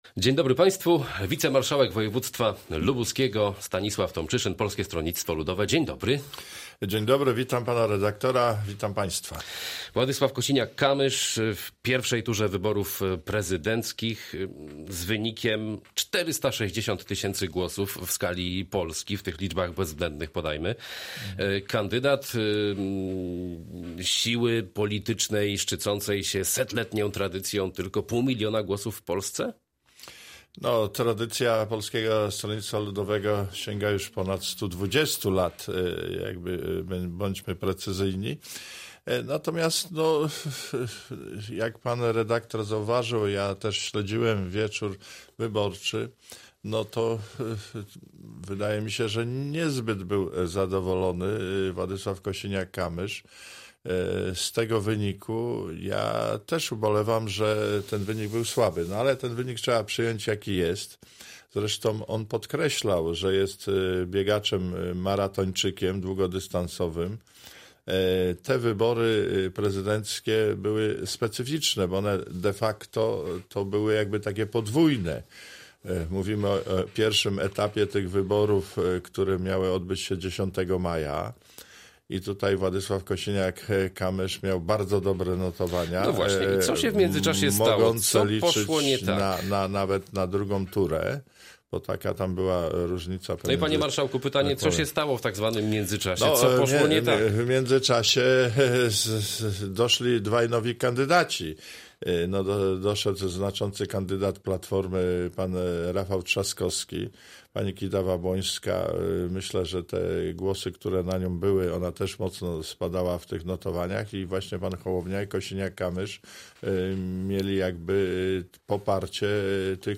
Z wicemarszałkiem województwa lubuskiego (PSL) rozmawia